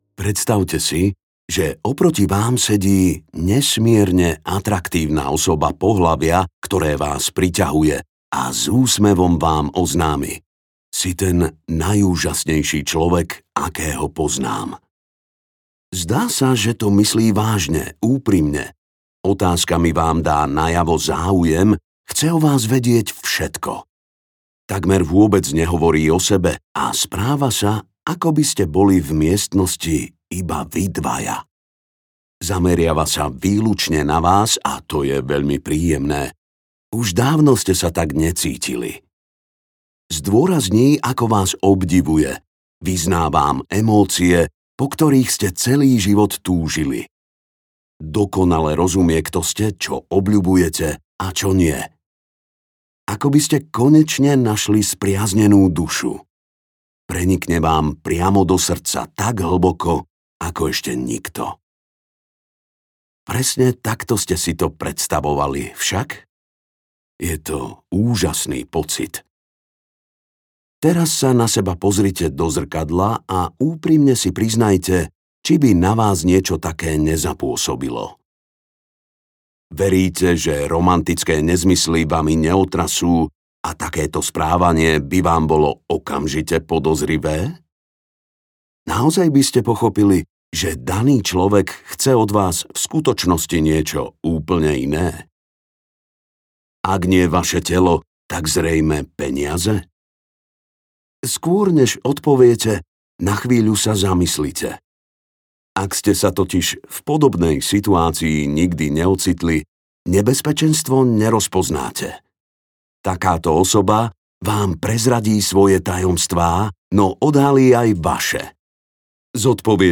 Ukázka z knihy
obklopeny-psychopatmi-audiokniha